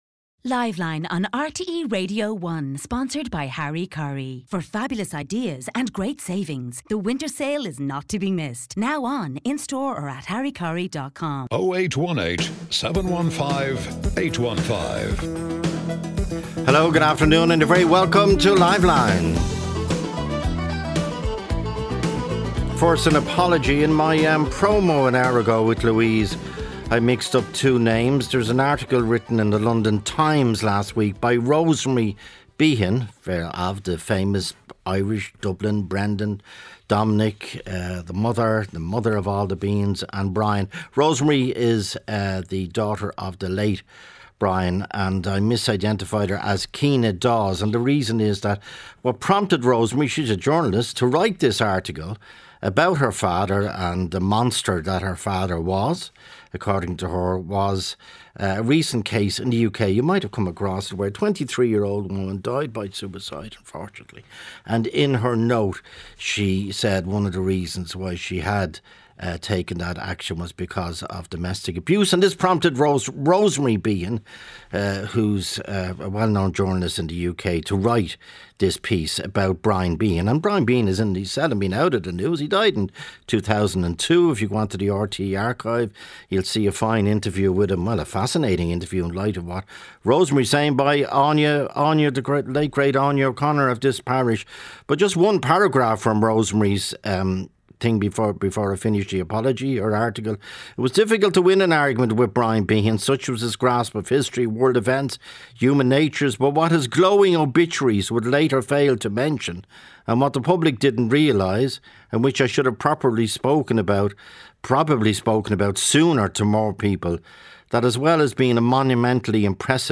Joe Duffy talks to the Irish public about affairs of the day.